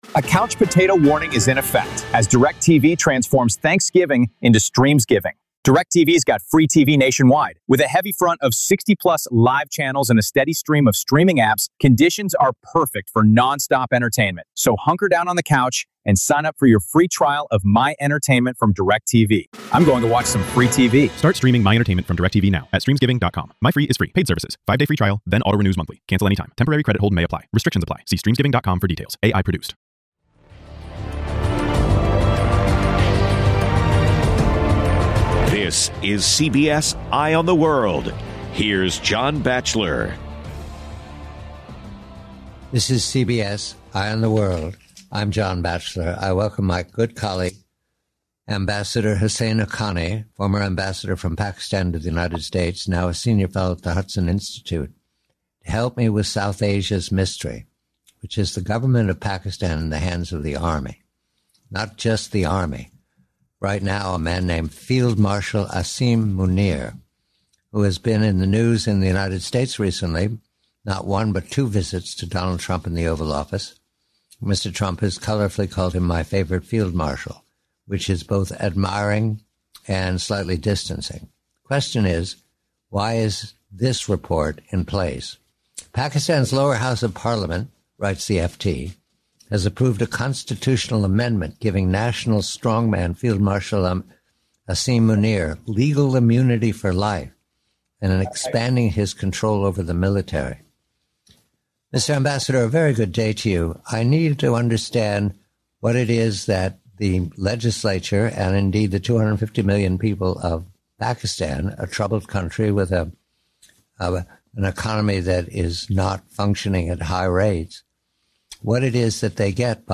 Guest: Ambassador Husain Haqqani